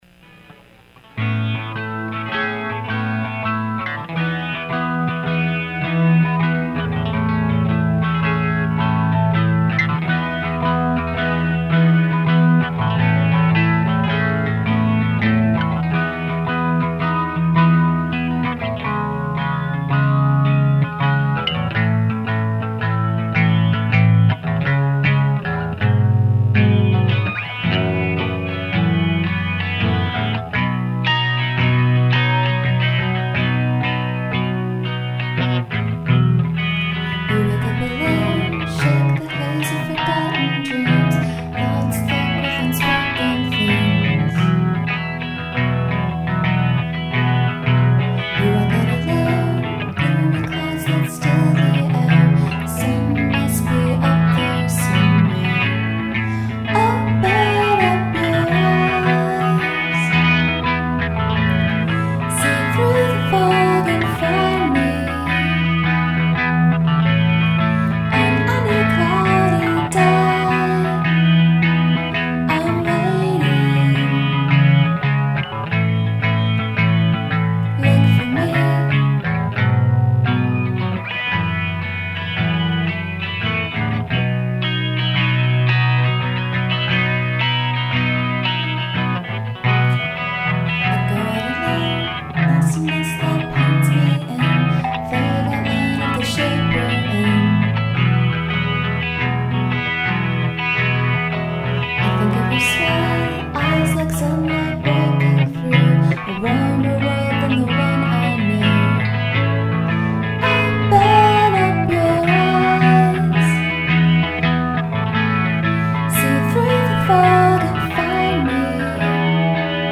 this is the first recording of my nonband! it is a live studio recording. i wrote the words and melody and someone else wrote and played the guitar part. i think the words are a little ungainly in a few places, particularly the lines with "gloomy" and "mist" in them. and the line "i think of your smile" is so gross. i'm pretty happy with the chorus, i think it walks a fine cheesy line but for some reason it seems more cute than grating. i like the end a lot, although maybe the melody does not make sense in context. there's going to be a bit more of an outtro but it hasn't been written yet. i'll keep updating the mp3 when we add more to it.